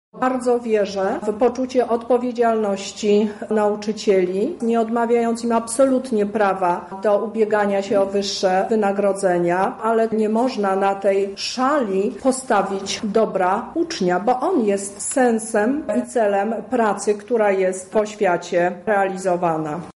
Strajk nauczycieli był tematem konferencji, w której wzięła udział lubelska kurator oświaty, Teresa Misiuk
-mówi Teresa Misiuk, lubelska kurator oświaty